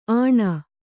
♪ āṇa